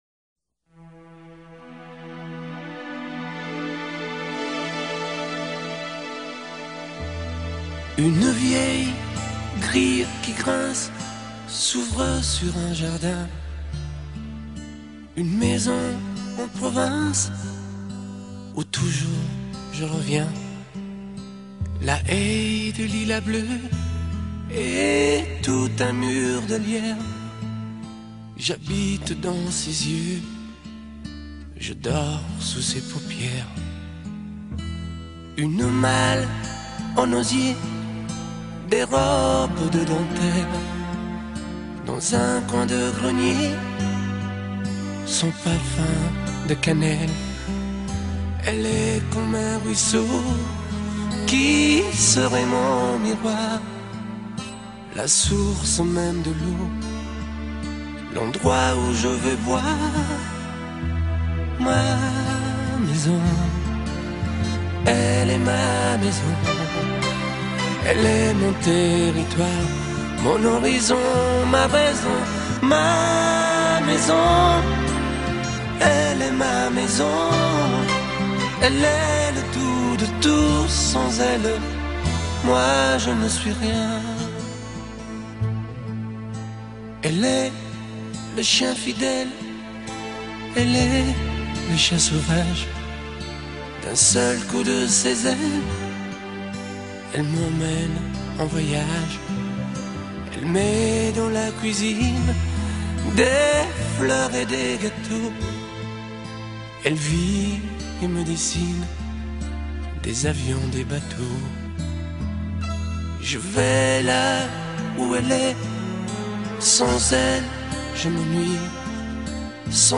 А зачем?Качество ведь записи хорошее.